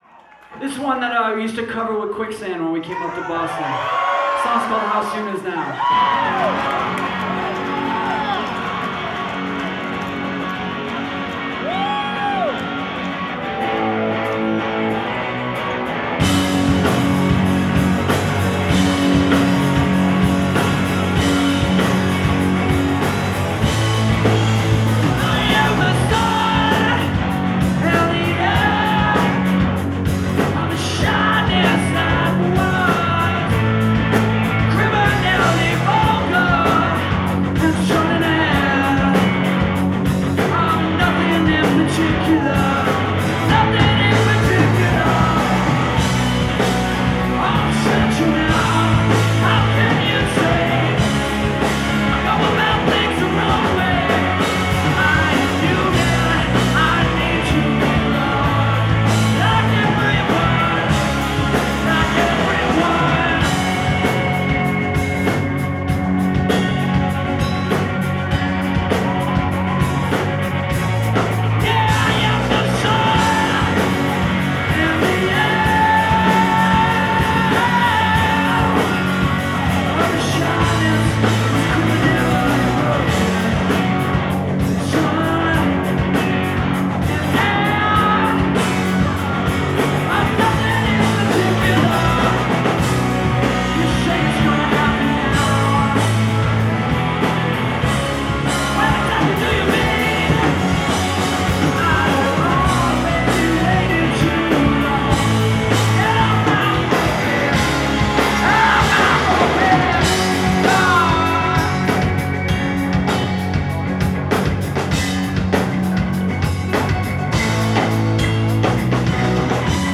live at Brighton Music Hall, Boston, 3/5/2011